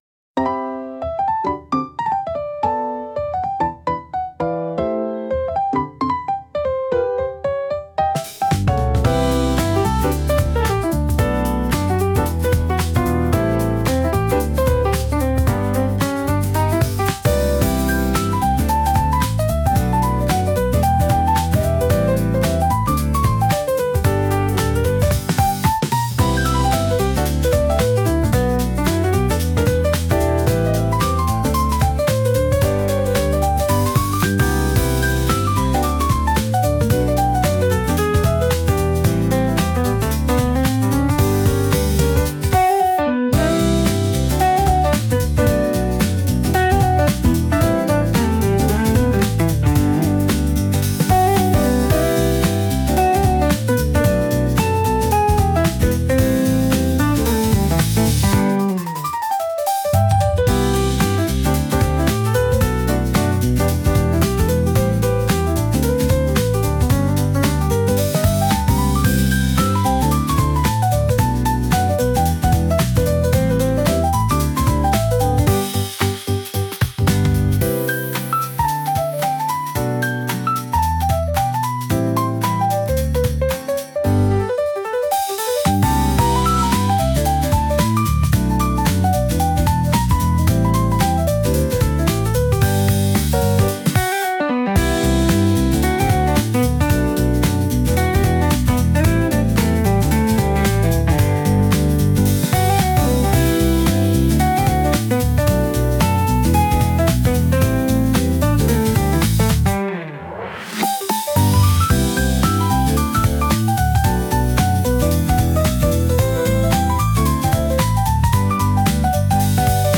気持ちよく繁華街を歩くときのBGM